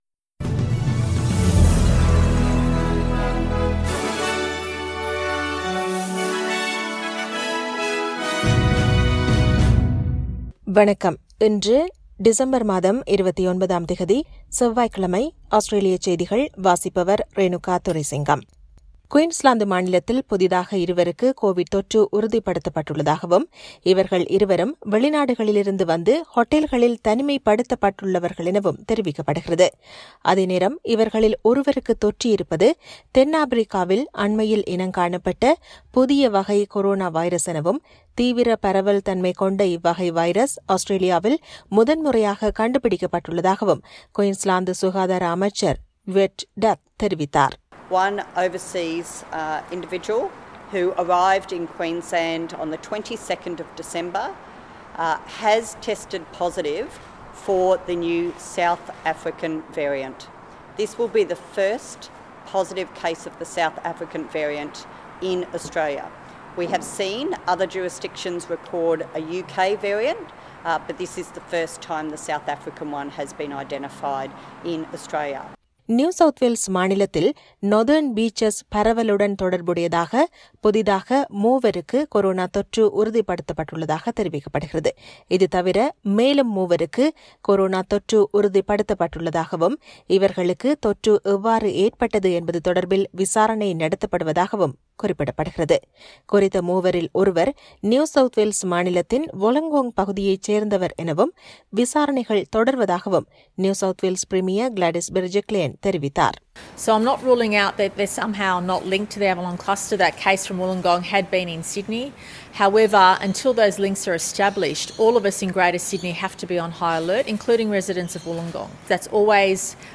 Australian news bulletin for Tuesday 29 December 2020.